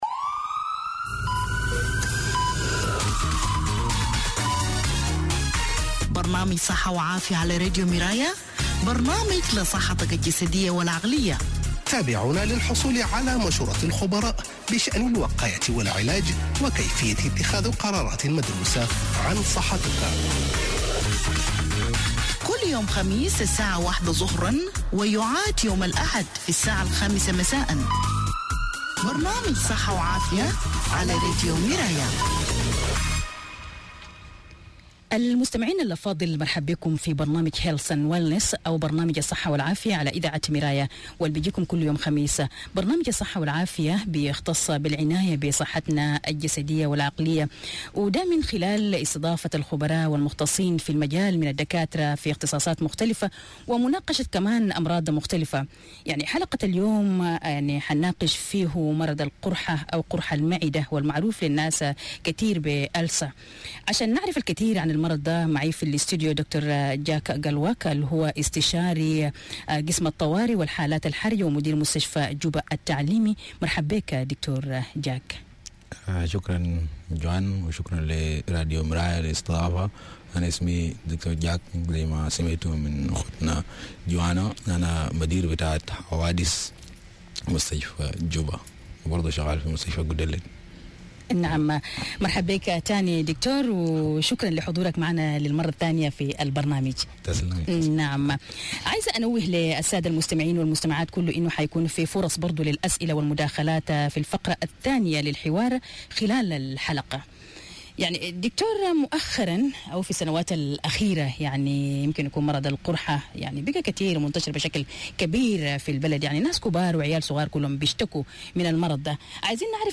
On Radio Miraya’s Health and Wellness program, we discussed stomach ulcers, also known as peptic ulcers, a common health issue affecting many people nationwide.